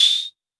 062 CR78 Tamb.wav